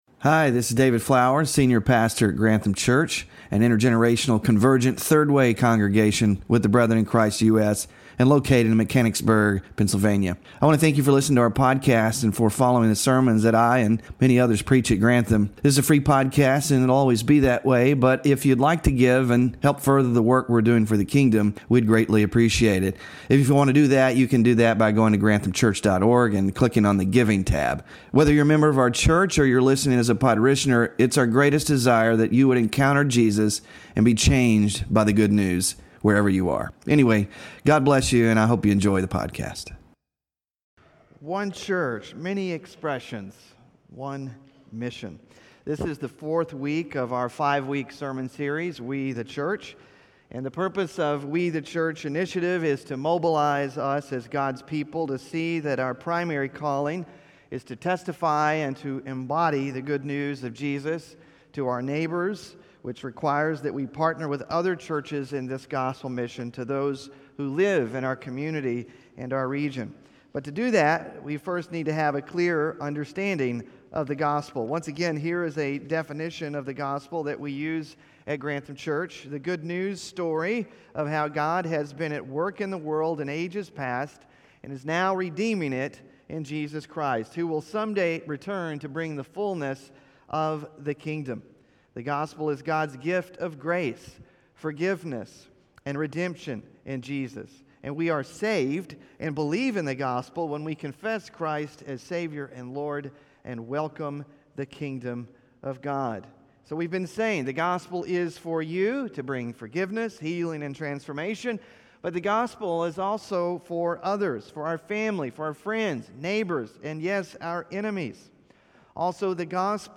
WORSHIP RESOURCES CHURCH TOGETHER (4TH OF 5 IN SERIES) SMALL GROUP QUESTIONS WE THE CHURCH 5-WEEK SERIES (FEB 2-MAR 2) BULLETIN (2-23-25)